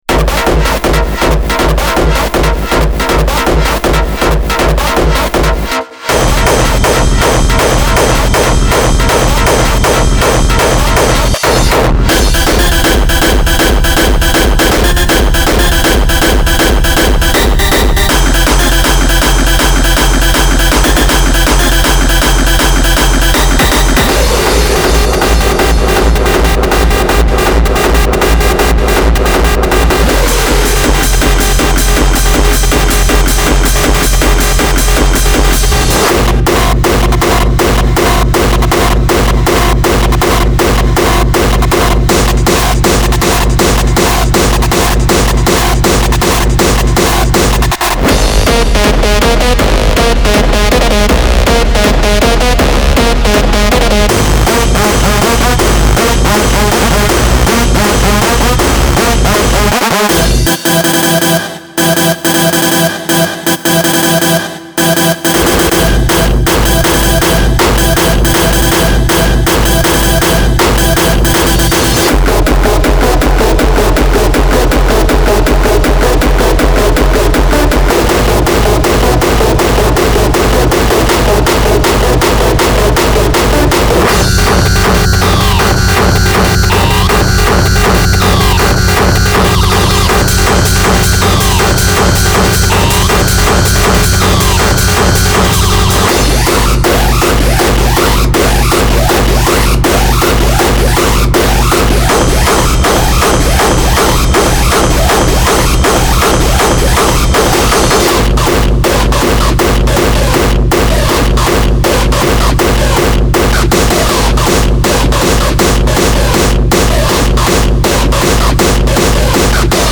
Genre:Hard Dance
このパックは、ハードテクノ、ハードコアレイブ、インダストリアル向けに鋭く研ぎ澄まされたサンプルを提供します。
ちなみに、このサンプルパックは160BPMでテンポ固定されており、現代のハードテクノに最適なテンポです。
114 Kick Loops
105 Synth Loops
8 Screech Stabs (Wet & Dry)